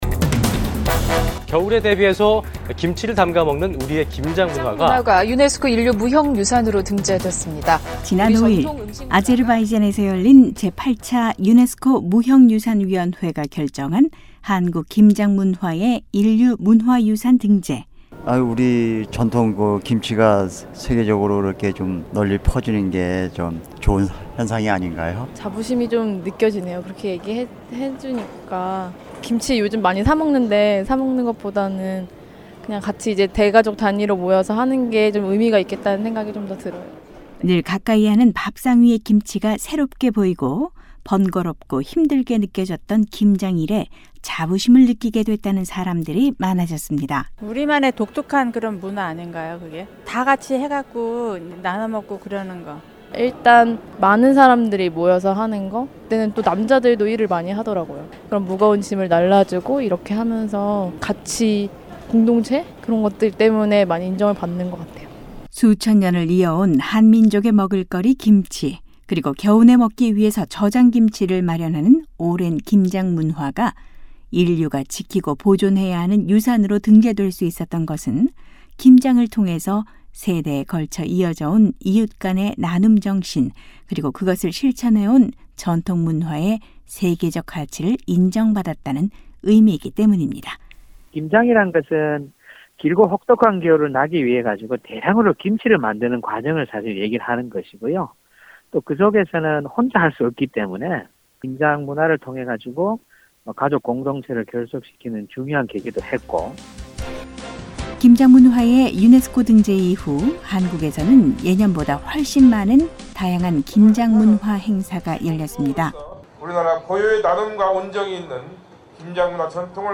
한국의 이모저모를 알아보는 ‘안녕하세요. 서울입니다’ 순서입니다. 오늘은 유네스코 인류무형유산에 한국의 ‘김장문화’가 등재된 이후, 한층 자부심이 높아진 한국 사람들의 목소리를 전해드립니다.